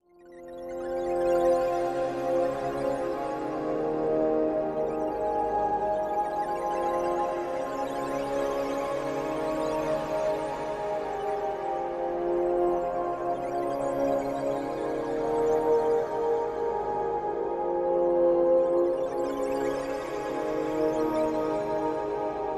标签： 85 bpm Cinematic Loops Pad Loops 3.80 MB wav Key : Unknown
声道立体声